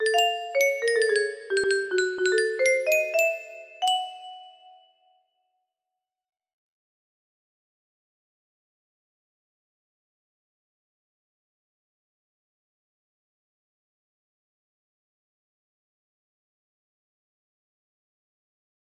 2222 music box melody